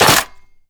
gun_chamber_jammed_02.wav